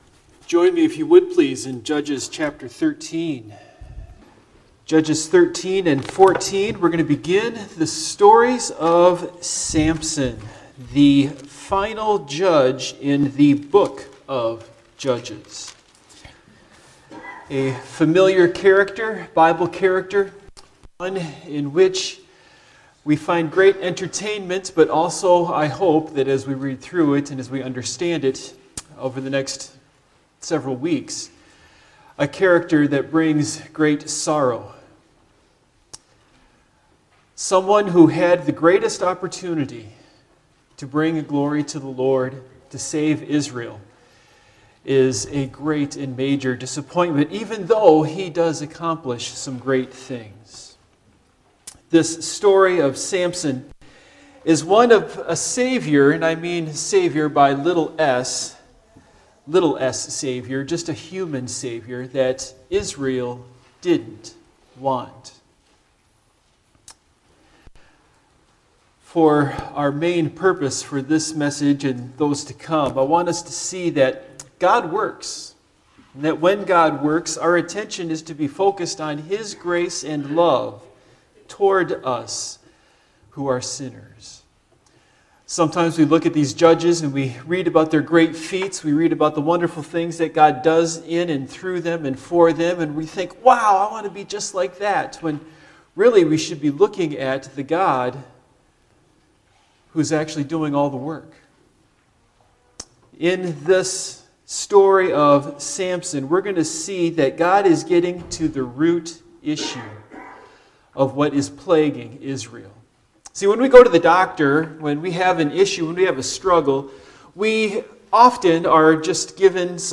Judges Passage: Judges 13 - 14 Service Type: Morning Worship « A Canaanized